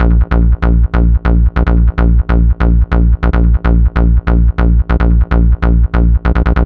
BL 144-BPM 3-A#.wav